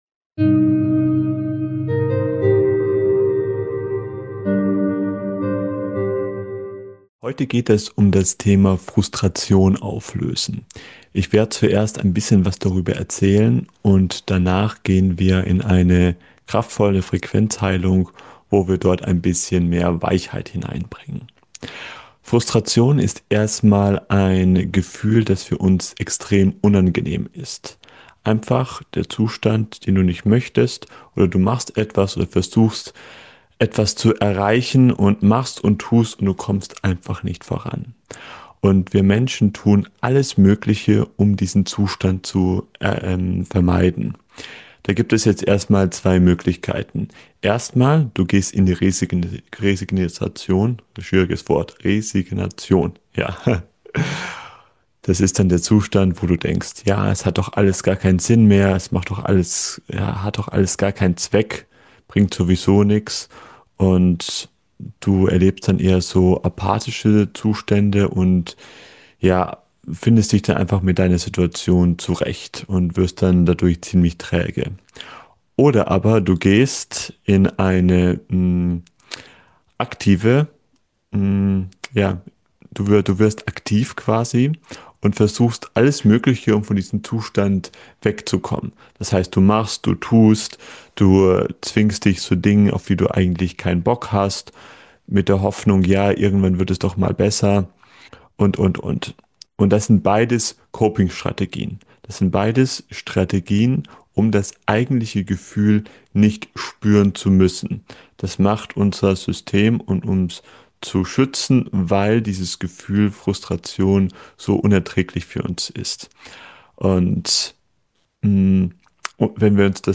#134 Wie du dich von Frust befreist - Mit Heilmeditation.
Ich zeige, warum das Aushalten und Annehmen von Frust der Schlüssel zur echten Veränderung ist, statt ihn nur zu verdrängen oder dagegen anzukämpfen. Gemeinsam tauchen wir in eine kraftvolle Heilmeditation ein, die dir hilft, deine Emotionen sanft zu integrieren und deinem Nervensystem Ruhe zu schenken.